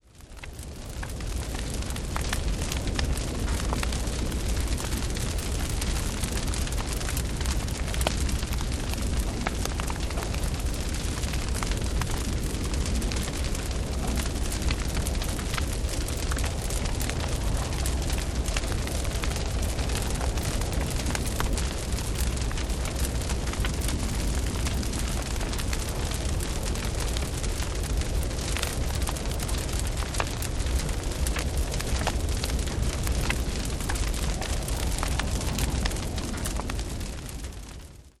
6 Stunden Kaminfeuer mit Schneesturmgeräuschen
44.1 kHz / Stereo Sound
Loop von Sound/Musik: Nein
Hoerprobe-Schneesturm.wav